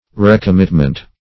Recommitment \Re`com*mit"ment\ (-ment)